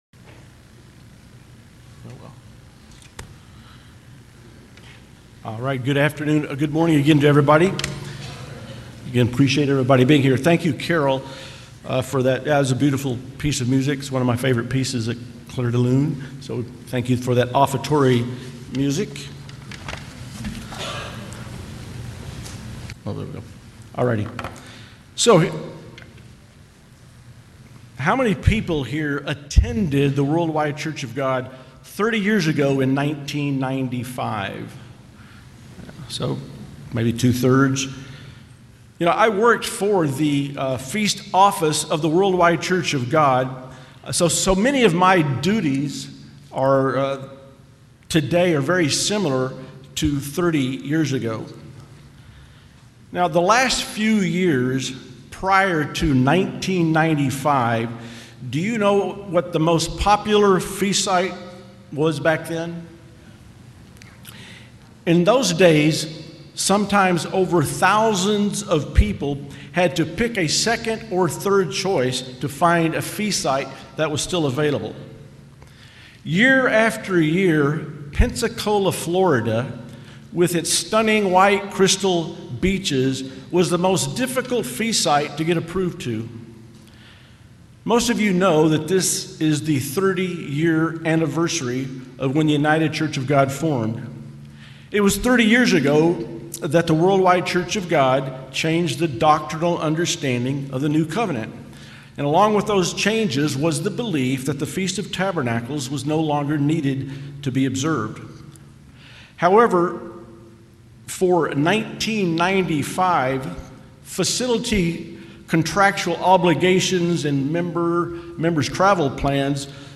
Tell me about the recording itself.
Given in Temecula, California